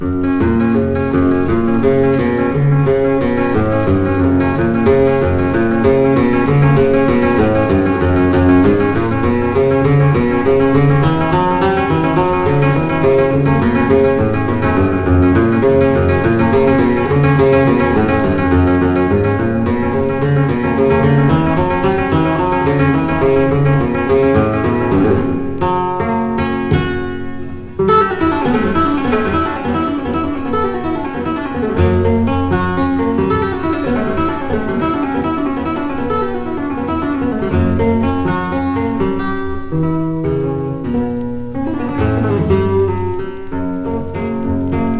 スペイン・マドリッド出身のギタリスト
フラメンコスタイルと言えば彼と思える演奏曲の数々、抑揚の効いた超絶技巧で繰り出される音は強烈の一言。
World, Flamenco　USA　12inchレコード　33rpm　Stereo